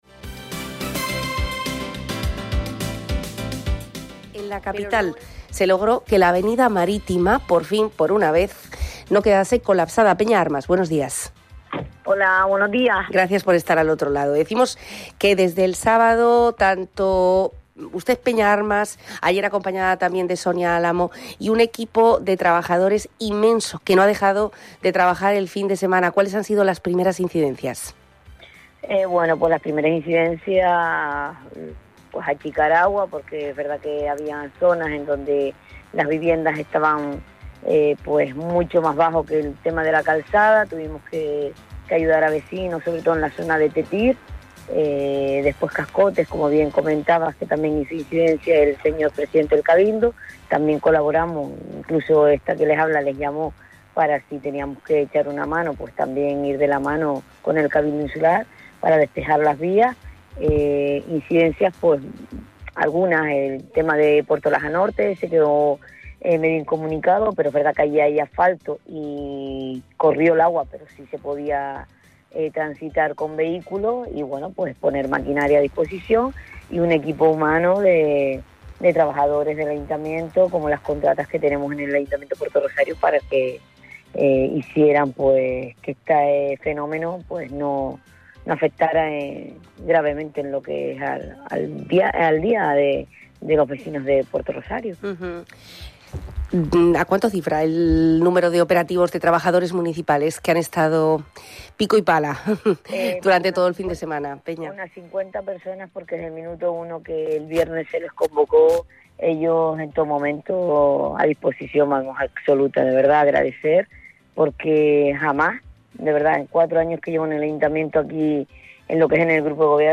Así lo ha explicado la concejal del consistorio capitalino Peña Armas.
Todos los datos en cuanto a los daños los ha explicado en El Magacín y la entrevista se puede escuchar aquí: Los operarios siguen en Puerto del Rosario resolviendo incidencias